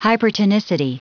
Prononciation du mot hypertonicity en anglais (fichier audio)
Prononciation du mot : hypertonicity